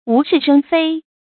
注音：ㄨˊ ㄕㄧˋ ㄕㄥ ㄈㄟ
無事生非的讀法